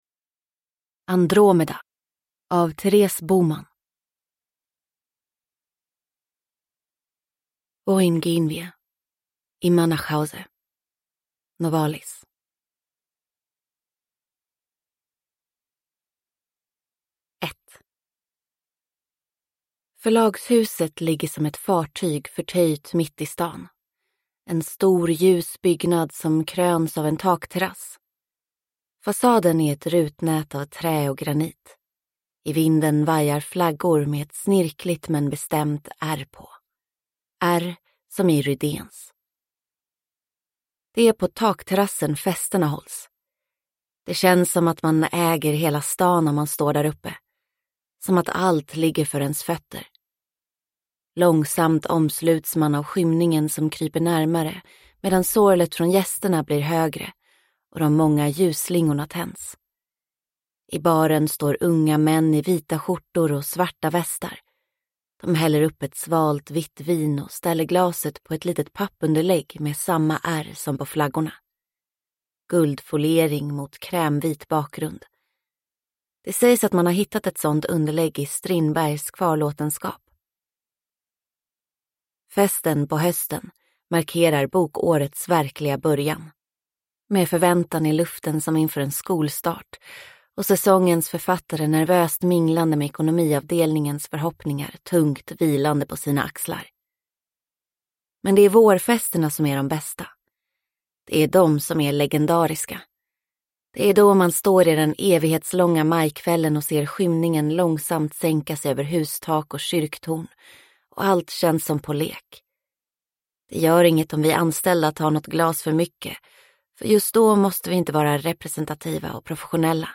Andromeda – Ljudbok – Laddas ner